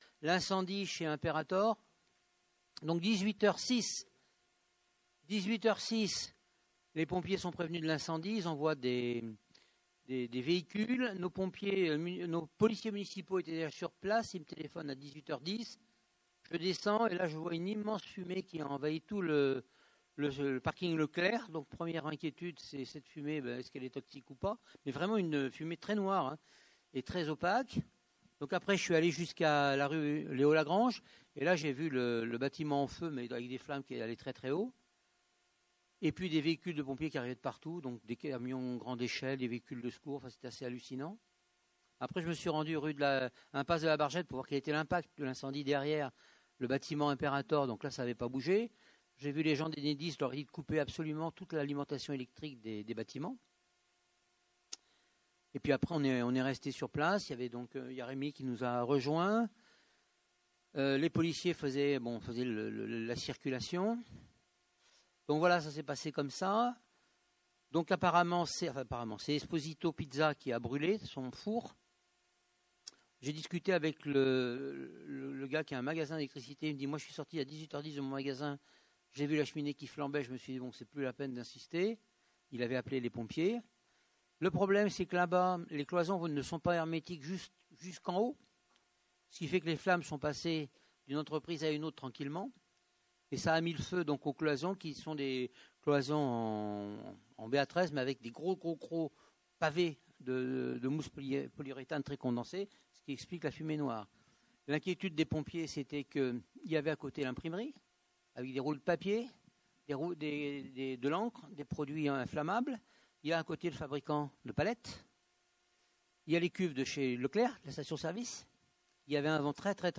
Conseil municipal du 24 mars 2025 | Mairie de Saint-Priest-en-Jarez